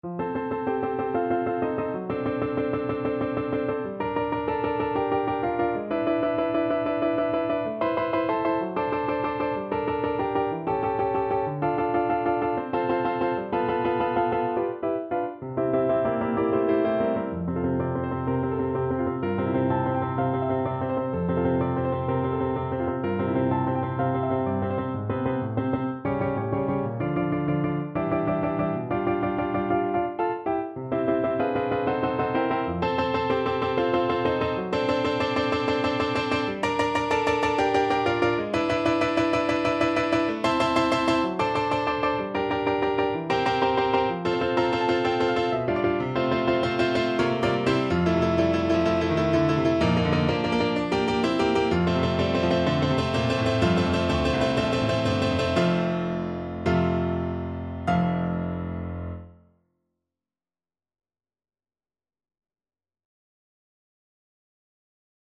~ = 63 Ziemlich rasch, leidenschaftlich
Classical (View more Classical Voice Music)